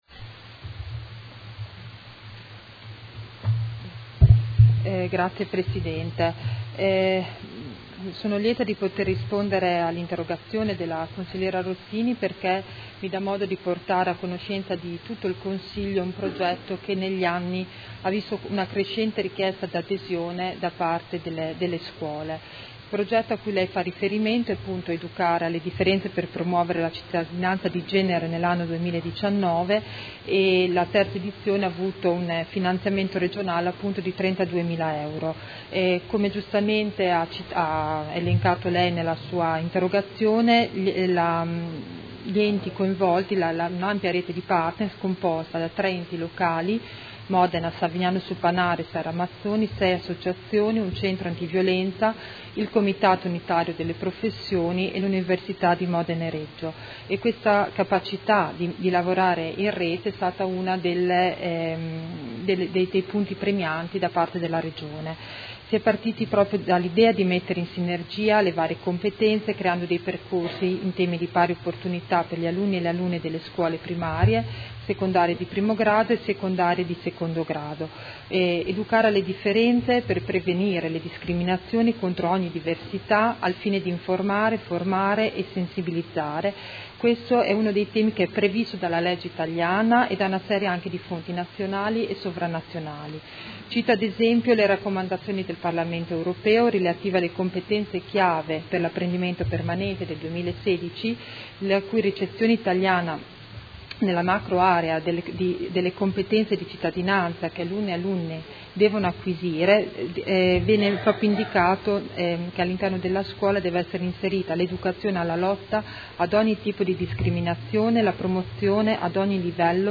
Grazia Baracchi — Sito Audio Consiglio Comunale